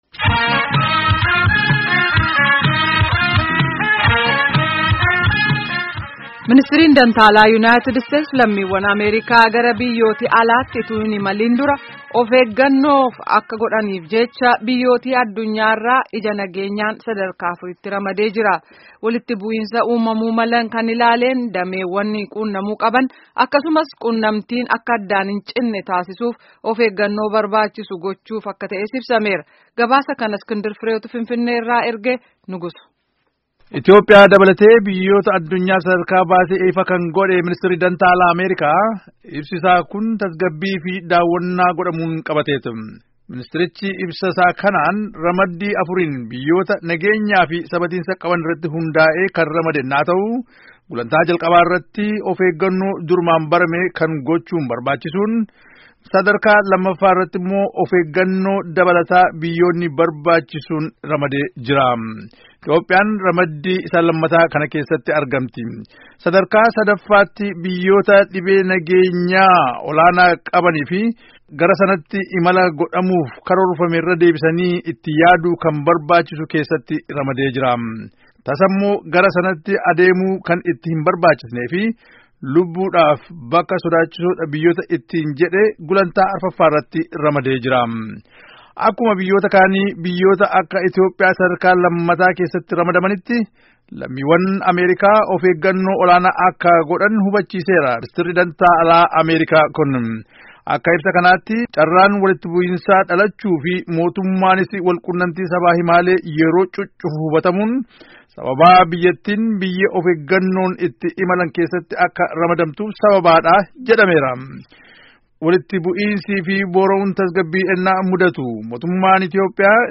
Gabaasaa guutuu